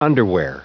Prononciation du mot underwear en anglais (fichier audio)
Prononciation du mot : underwear